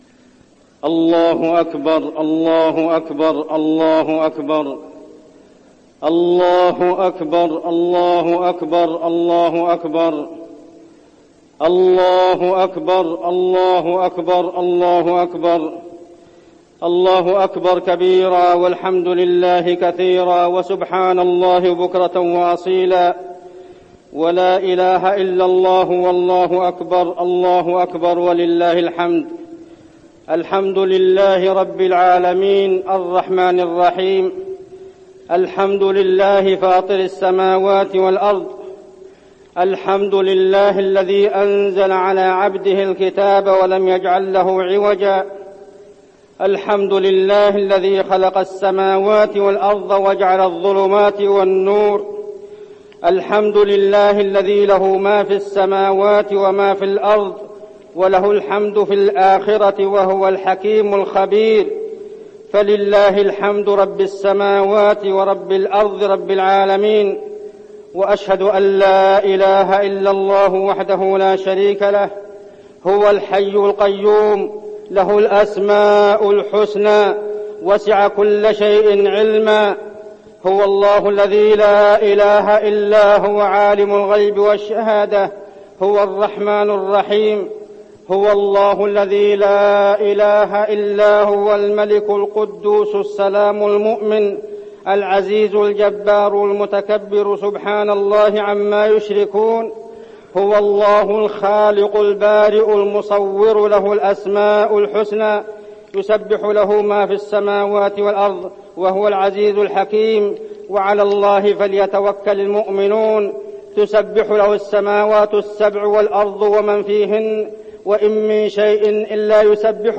خطبة الاستسقاء - المدينة- الشيخ عبدالله الزاحم
تاريخ النشر ١٦ جمادى الأولى ١٤١١ هـ المكان: المسجد النبوي الشيخ: عبدالله بن محمد الزاحم عبدالله بن محمد الزاحم خطبة الاستسقاء - المدينة- الشيخ عبدالله الزاحم